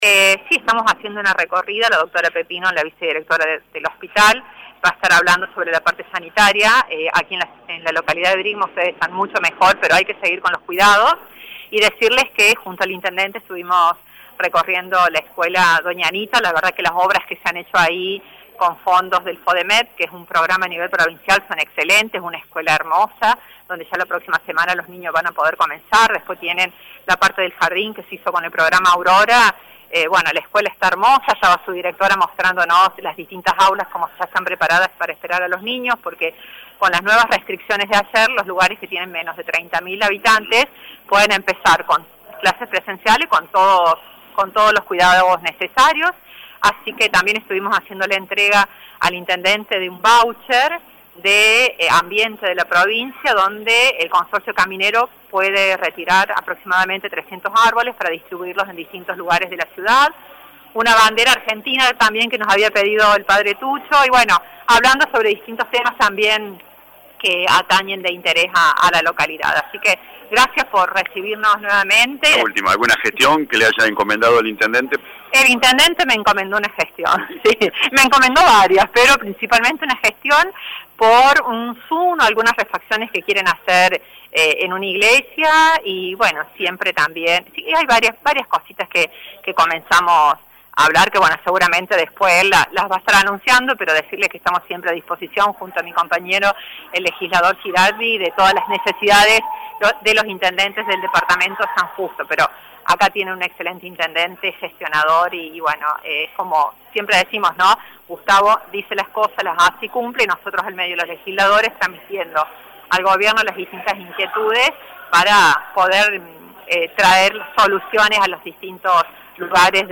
Alejandra Piasco habló con LA RADIO 102.9 durante su visita a la localidad. Hizo referencia a la recorrida por obras en el centro educativo, gestiones encomendadas por el Intendente, situación sanitaria y al Programa Punto Mujer Córdoba.